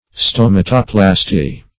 Search Result for " stomatoplasty" : The Collaborative International Dictionary of English v.0.48: Stomatoplasty \Stom"a*to*plas`ty\, n. [Gr.